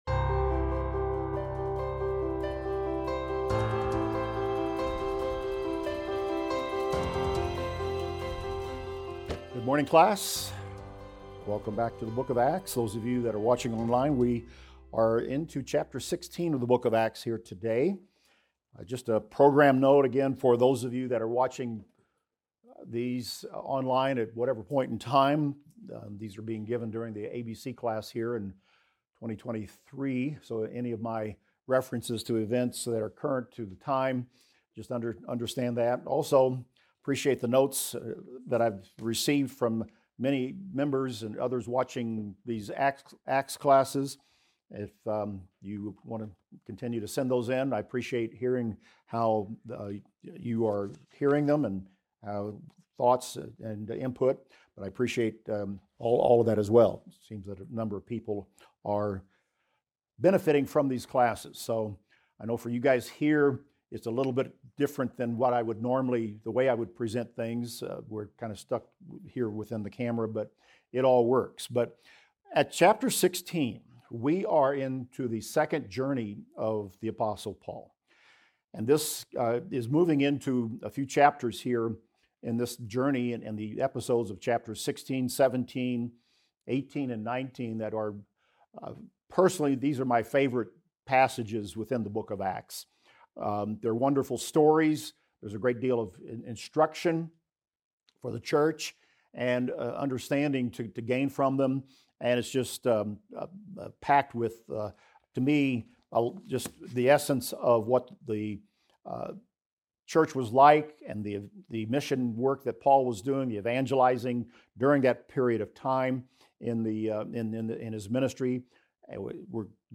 In this class, we will discuss Acts 16:1-38 and look at Timothy joining Paul and Silas in their travels, Paul's vision of the man of Macedonia, Lydia's conversion in Philippi and finally Paul and Silas in prison.